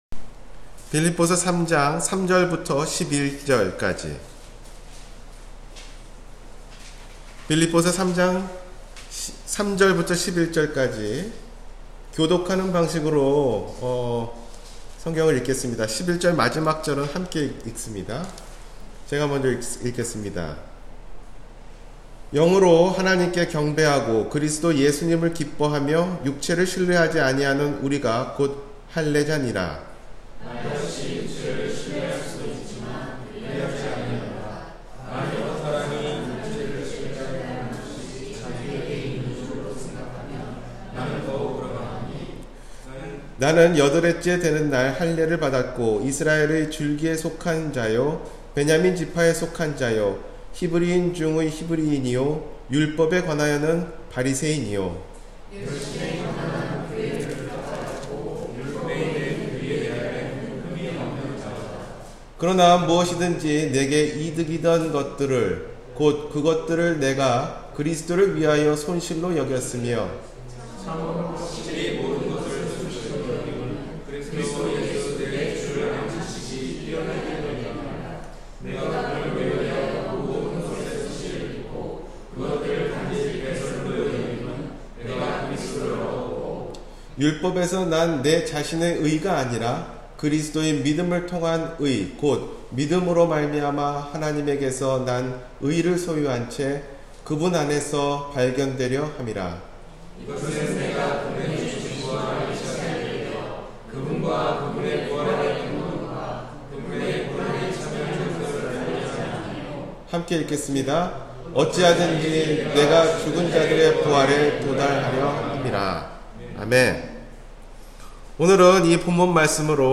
지금까지 내 인생 – 주일설교